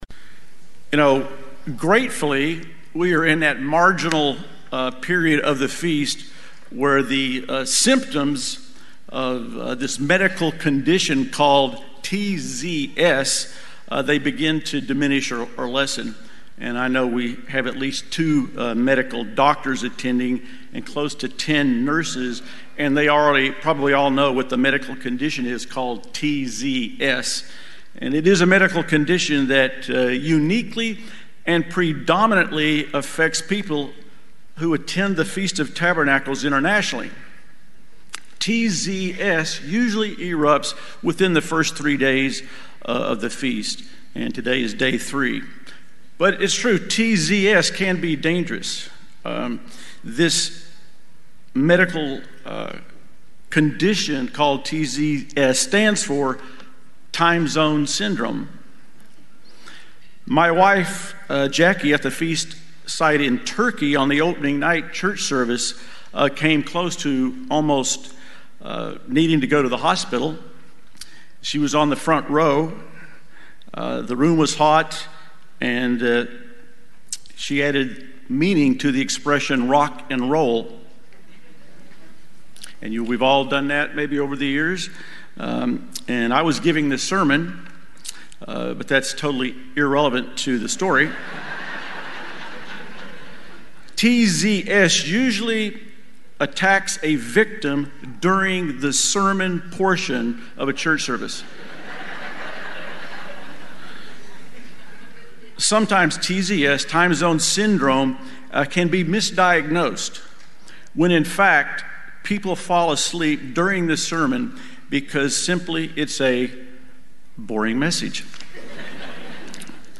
This message was given during the 2024 Feast of Tabernacles in Crete, Greece.